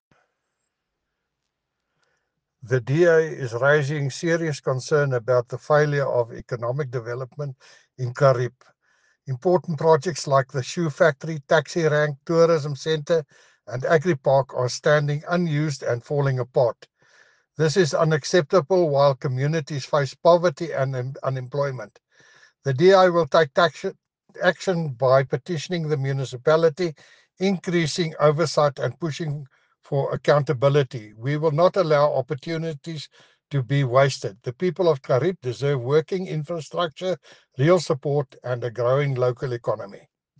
Afrikaans soundbites by Cllr Jacques van Rensburg and